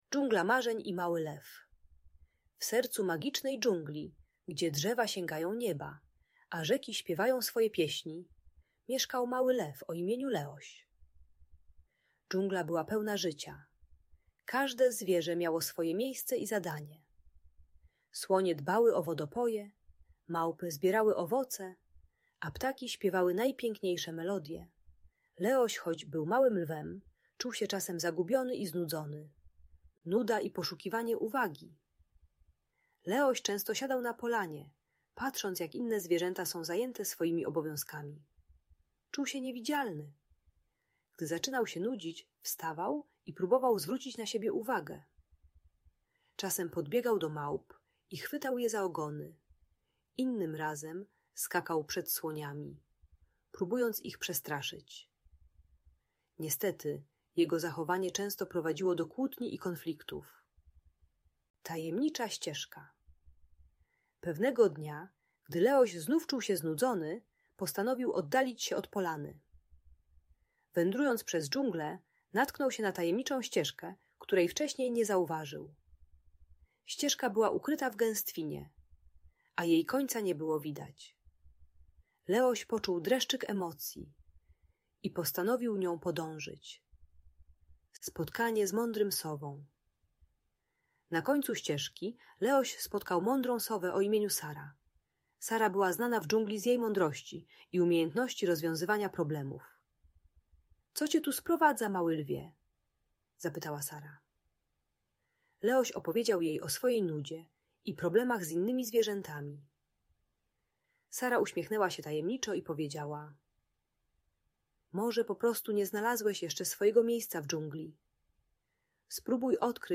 Dżungla Marzeń i Mały Lew - Przedszkole | Audiobajka